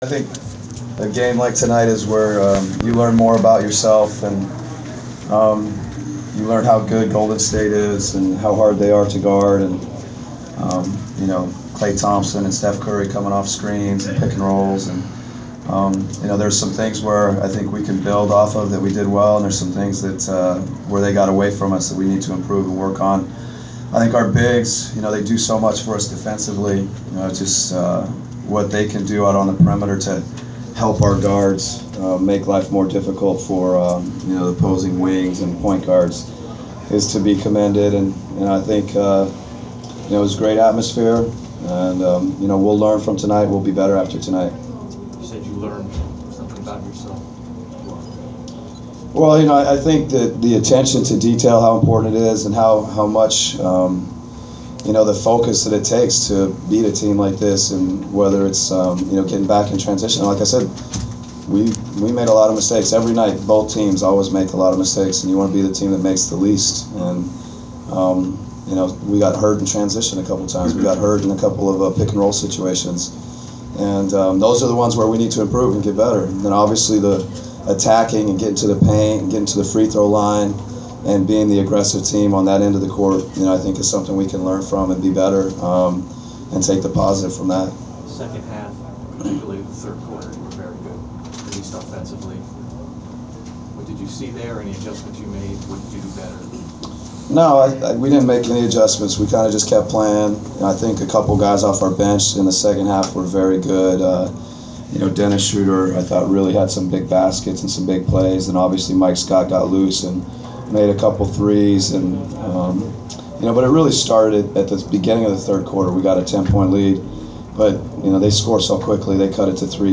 Inside the Inquirer: Postgame presser with Atlanta Hawks’ head coach Mike Budenholzer (2/6/15)
We attended the postgame presser of Atlanta Hawks’ head coach Mike Budenholzer following his team’s 124-116 win over the Golden State Warriors on Feb. 6. Topics included the defensive effort of the Hawks, the test the contest provided and the gameday atmosphere provided by the home crowd.